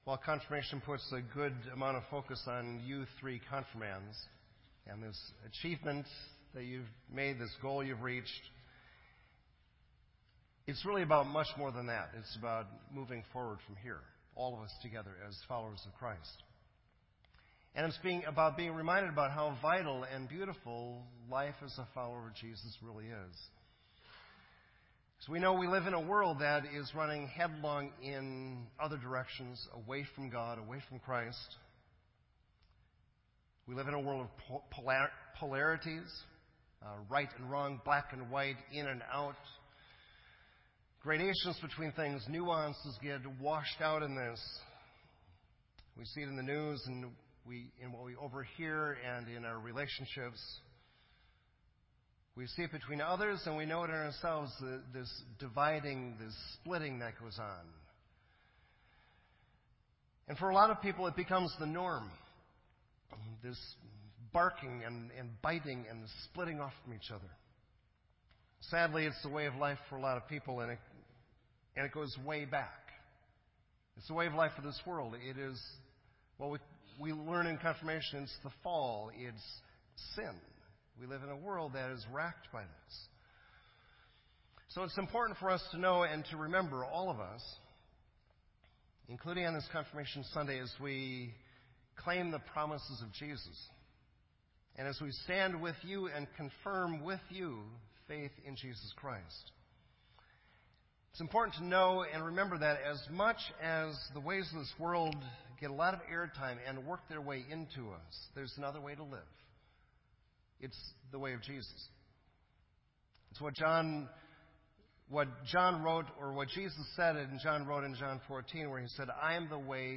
This entry was posted in Sermon Audio on May 15